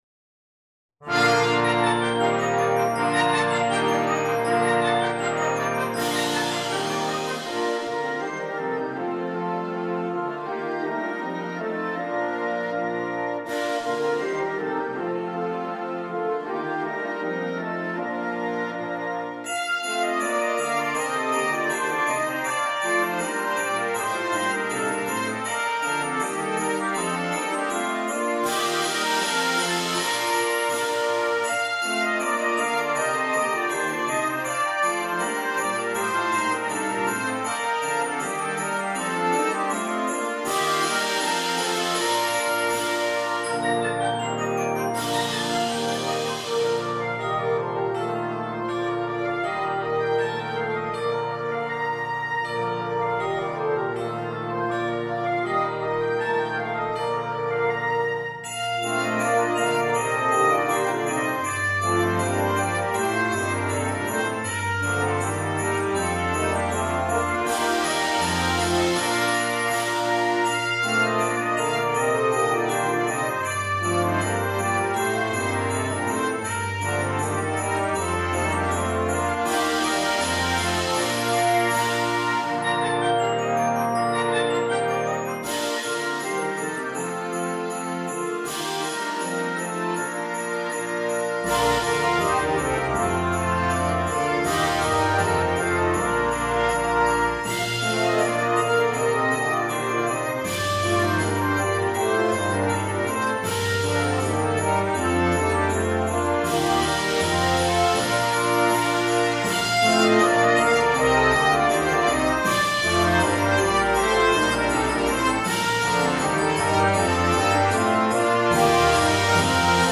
Ding-Dong-Merrily-On-High-Concert-Band.mp3